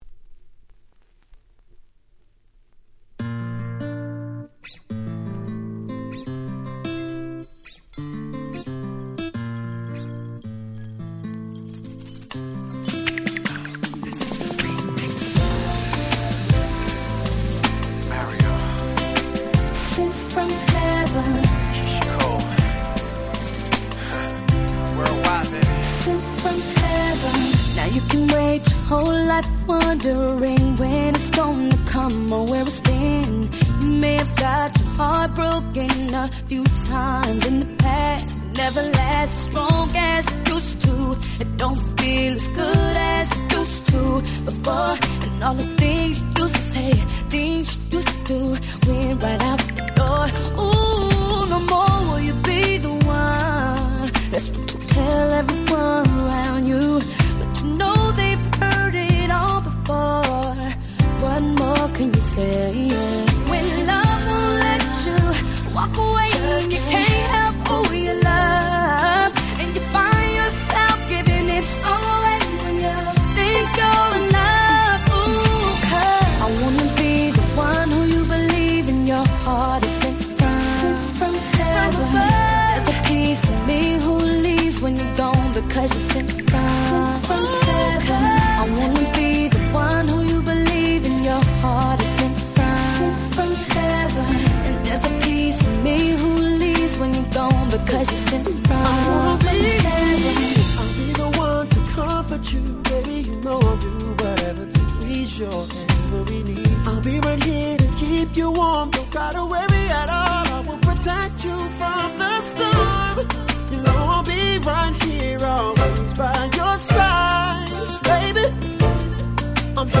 10' Very Nice R&B !!